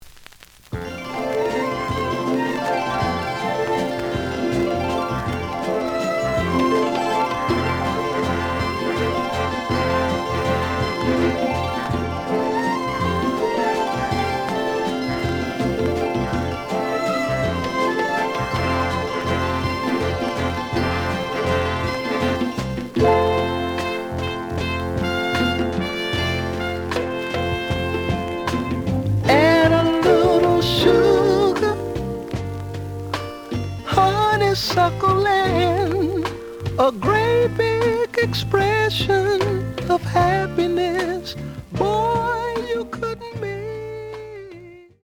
The audio sample is recorded from the actual item.
●Genre: Funk, 70's Funk
Edge warp.